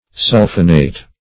sulphinate - definition of sulphinate - synonyms, pronunciation, spelling from Free Dictionary Search Result for " sulphinate" : The Collaborative International Dictionary of English v.0.48: Sulphinate \Sul"phi*nate\, n. (Chem.) A salt of a sulphinic acid.